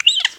squeak.wav